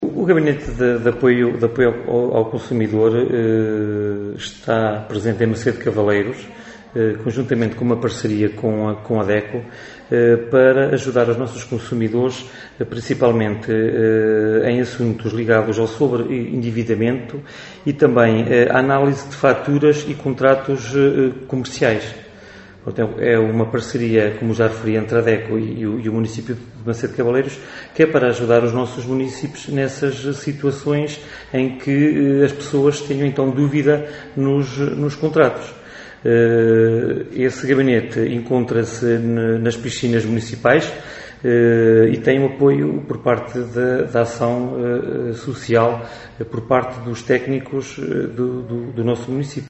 É José Luís Afonso, vereador do pelouro da Ação Social, quem explica melhor.